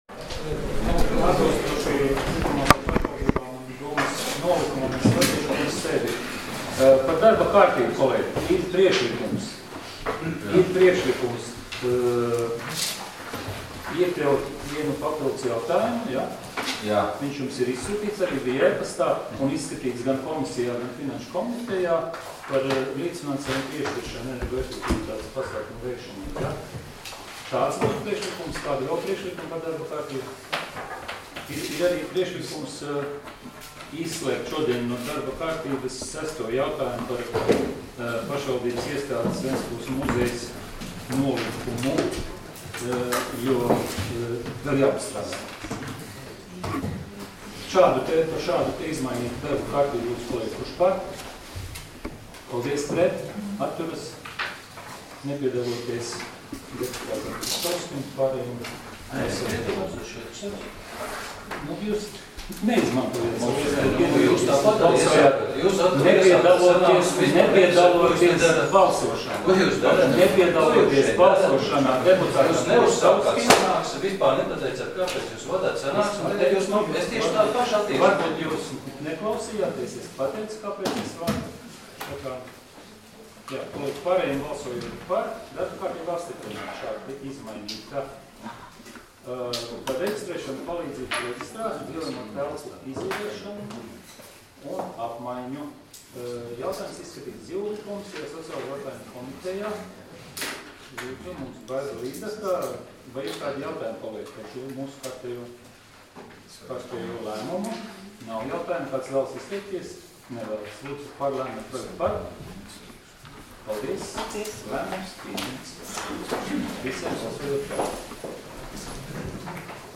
Domes sēdes 18.05.2018. audioieraksts